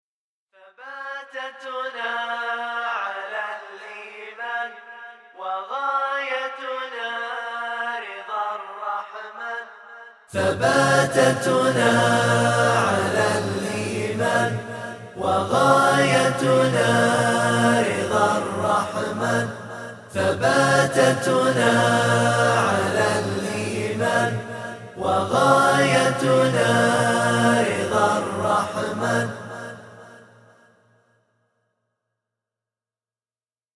من هو هذا المنشد :